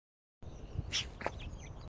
地址：上海市上海市浦东新区浦东南汇东滩湿地
20 针尾沙锥